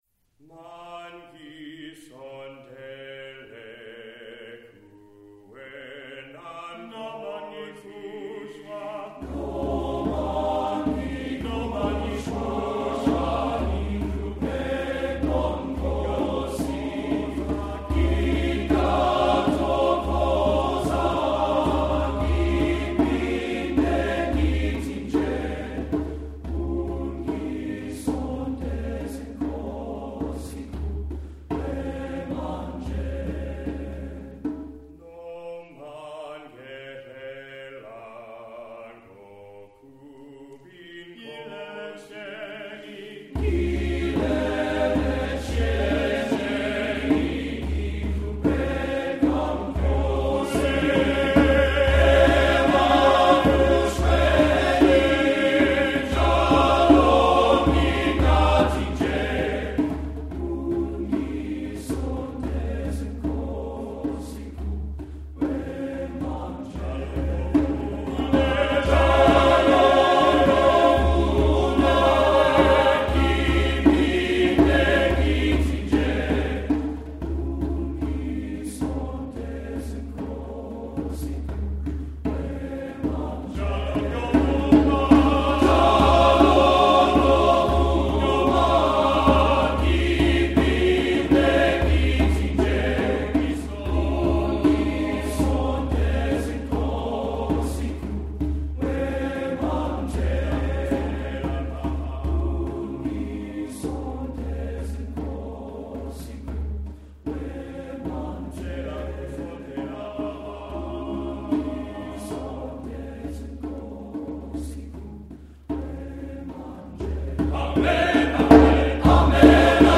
Composer: Traditional isiZulu Song
Voicing: TTBB a cappella with Solos